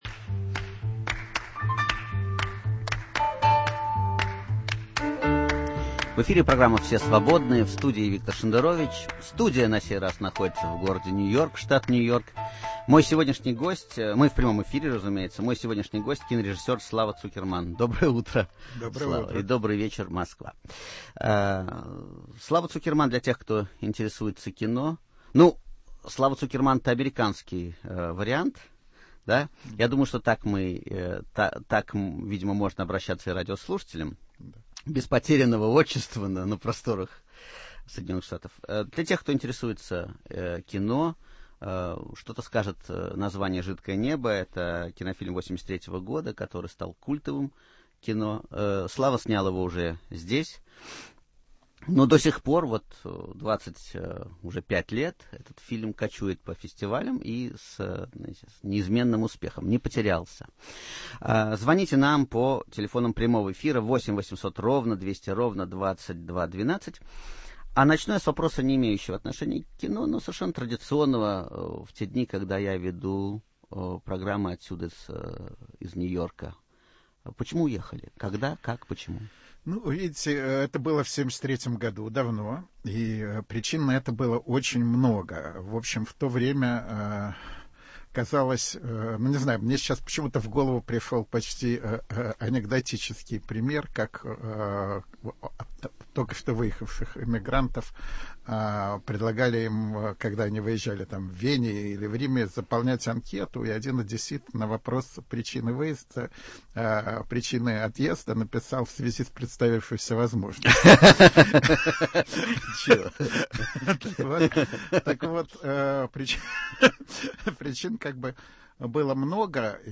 Свободный разговор на свободные темы. Гостей принимает Виктор Шендерович, который заверяет, что готов отвечать на любые вопросы слушателей, кроме двух: когда он, наконец, уедет в Израиль и сколько он получает от ЦРУ?